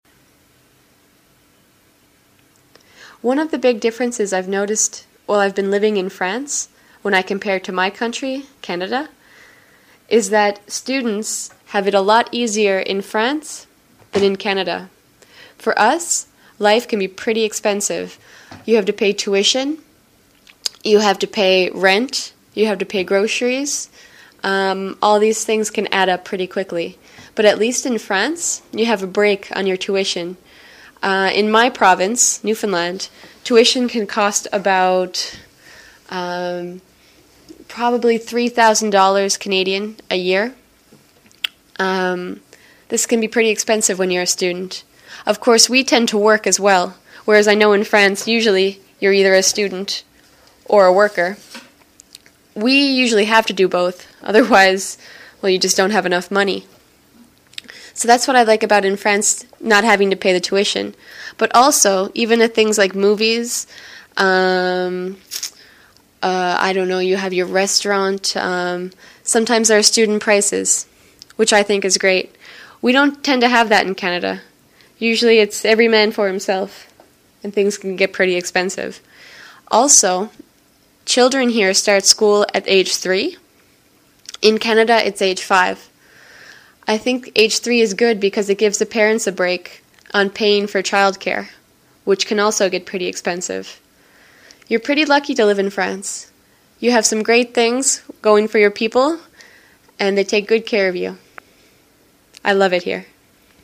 Accent
Canadien